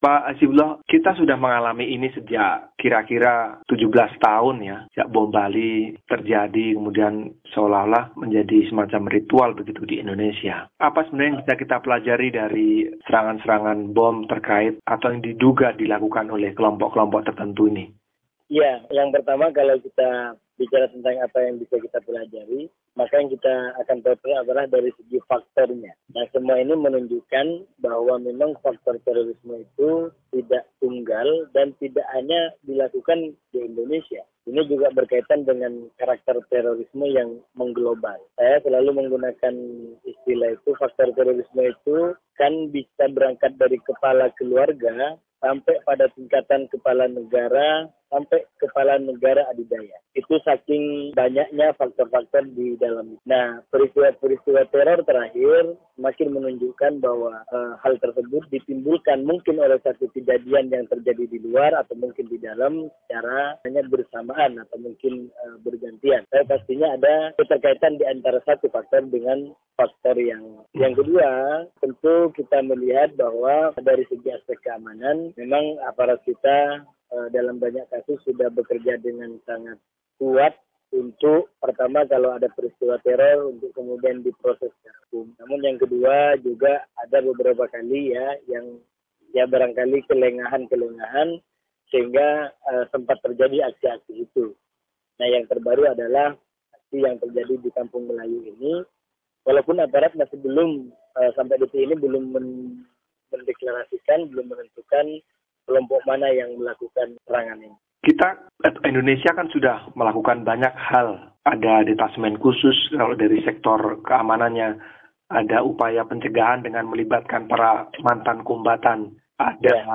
Wawancara dengan pakar terorisme dari Aliansi Indonesia Damai